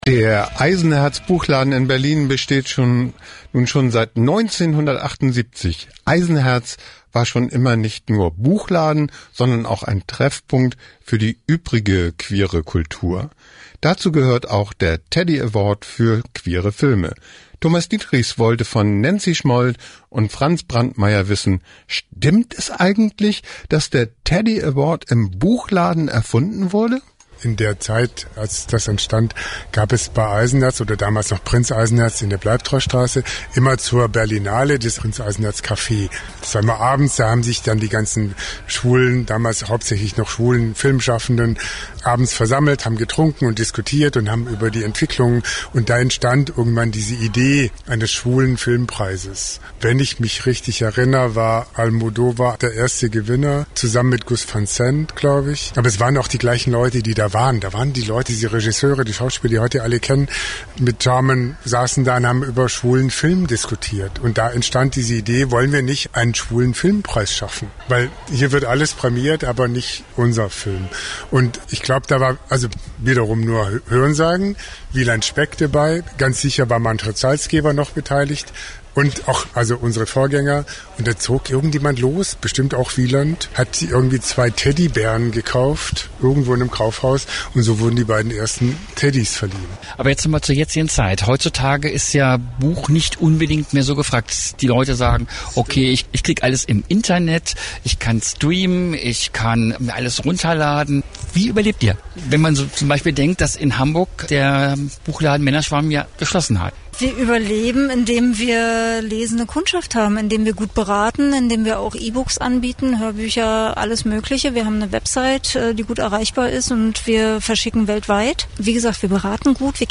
Bei der Respektpreisverleihung in Berlin
ein Interview